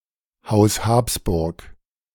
The House of Habsburg (/ˈhæpsbɜːrɡ/; German: Haus Habsburg [haʊs ˈhaːbsbʊrɡ]